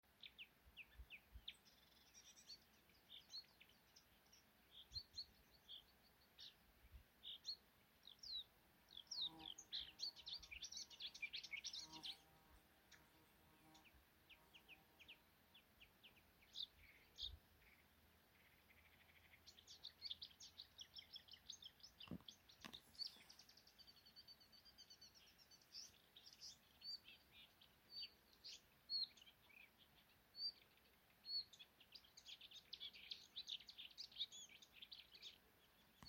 Marsh Warbler, Acrocephalus palustris
StatusSinging male in breeding season